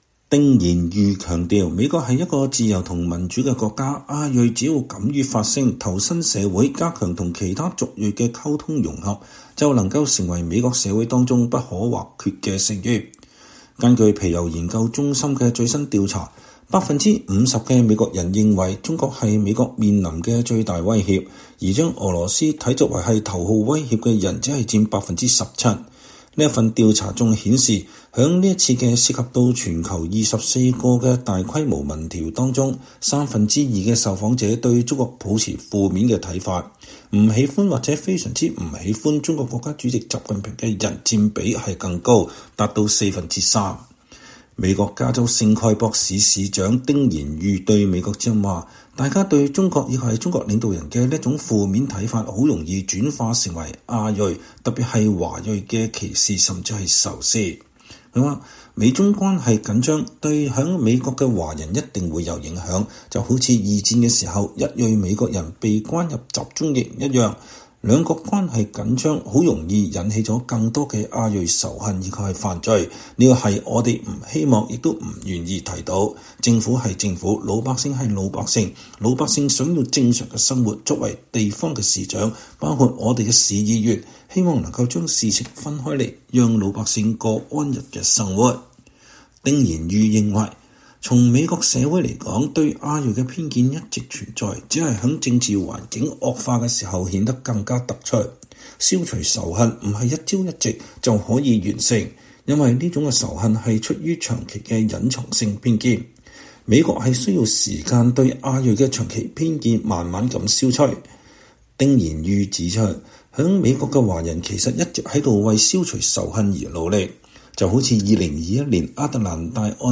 VOA專訪加州聖蓋博市市長丁言愉: 紮根美國擁抱民主